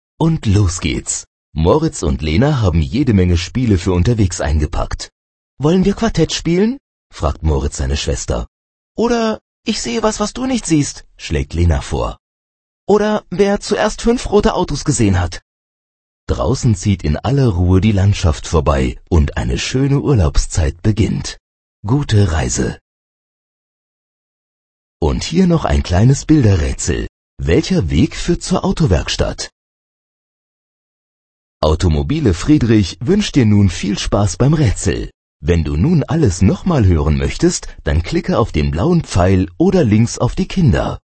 Hörbuch Seite 12